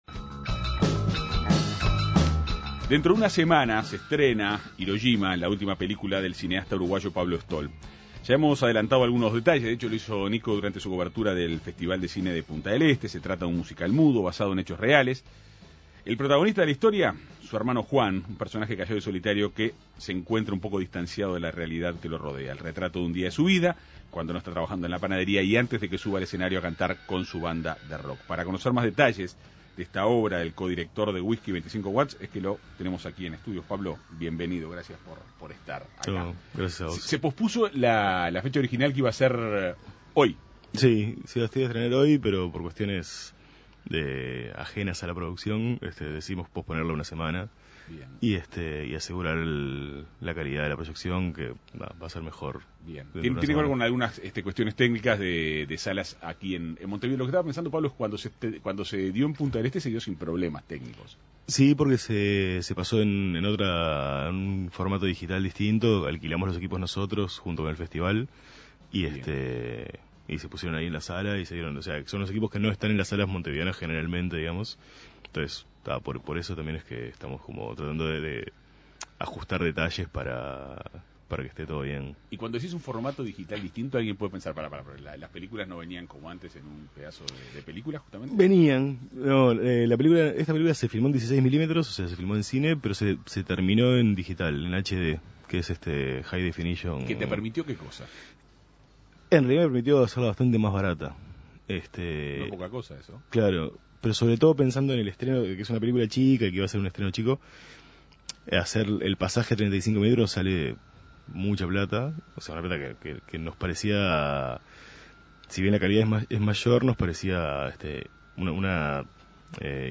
Para conocer más detalles de esta obra del co-director de Whisky y 25 Watts, En Perspectiva Segunda Mañana dialogó con el cineasta uruguayo.